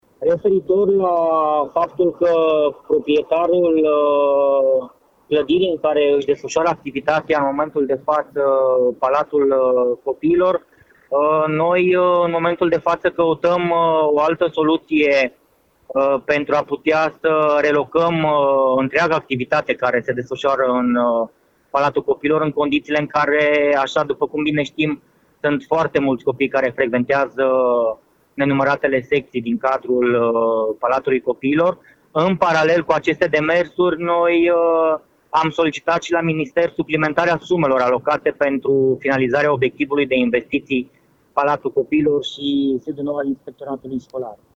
În paralel, se construieşte o clădire nouă, însă din cauza lipsei banilor şi a unui cablu de curent de înaltă tensiune, lucrările nu sunt gata, spune șeful Inspectoratului Şcolar Arad, profesorul Claudius Mladin: